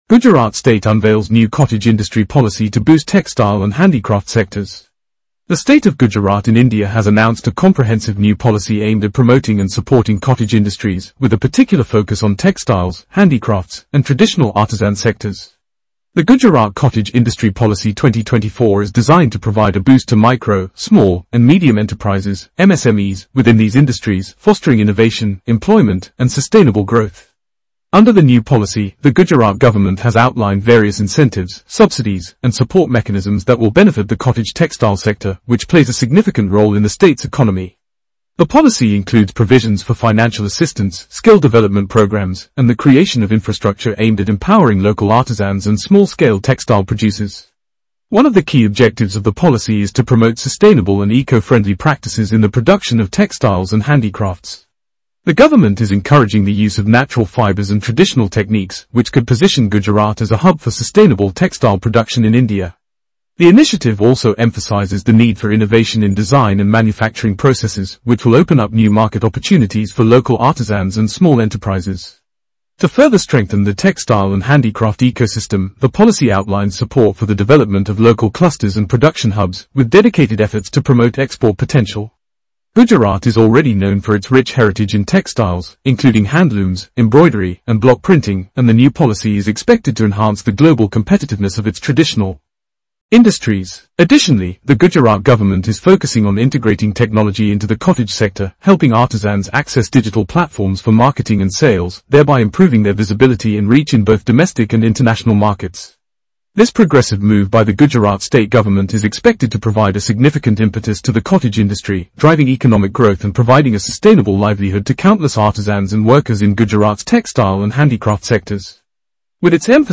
News Audio